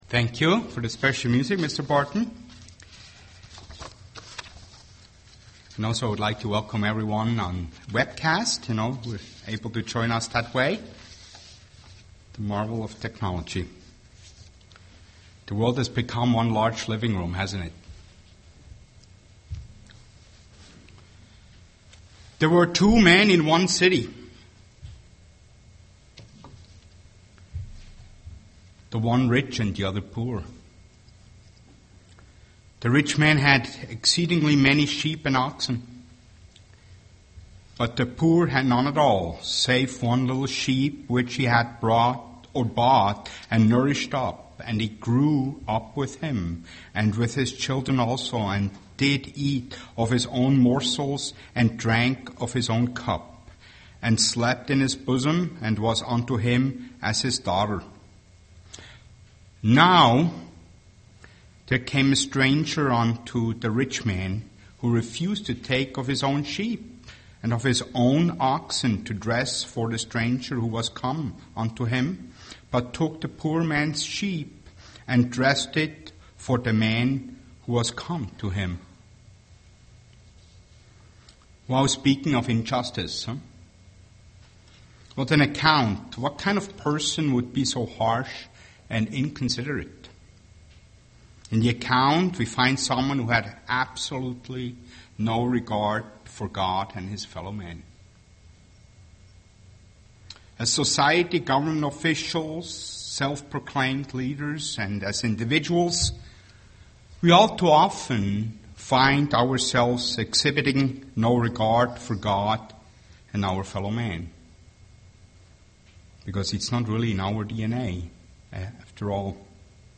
UCG Sermon self examination Studying the bible?
Given in Twin Cities, MN